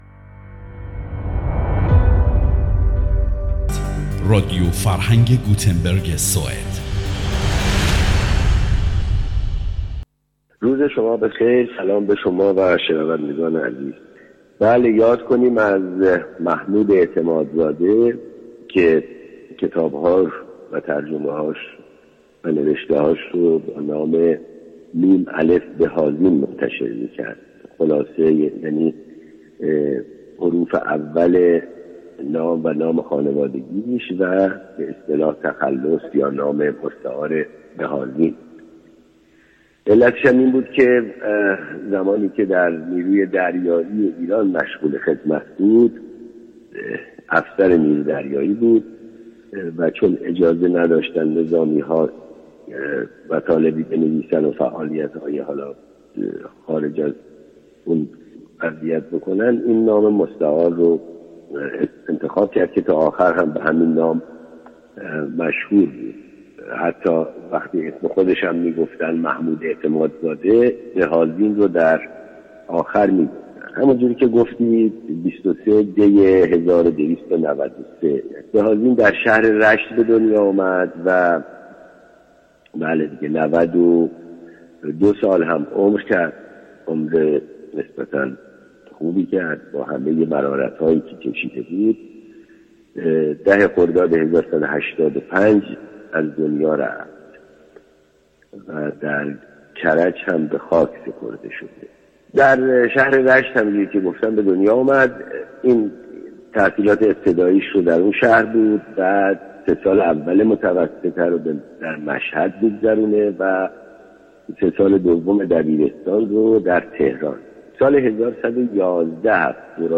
یادش گرامى و توجه دوستان را به شنیدن این گفتگو جلب مینماییم